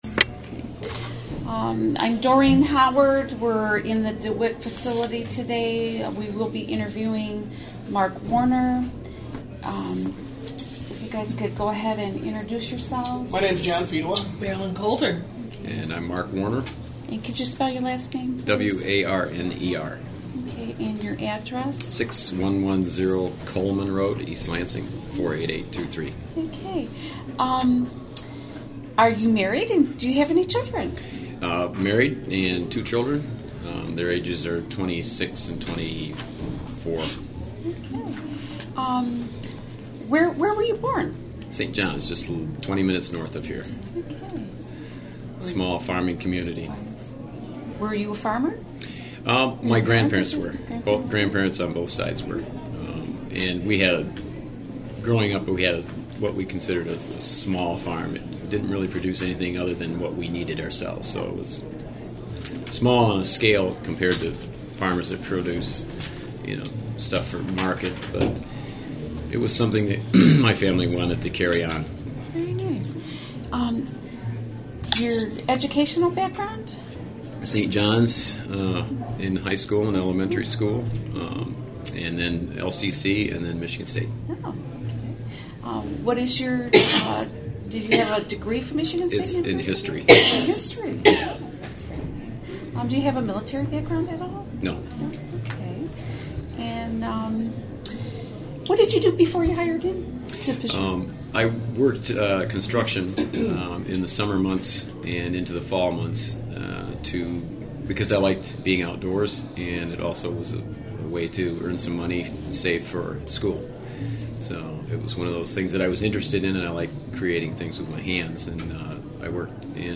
Recorded on Dec. 21, 2005 as part of the United Auto Workers Local 602/General Motors Oral History Project.